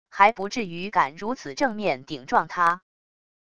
还不至于敢如此正面顶撞他wav音频生成系统WAV Audio Player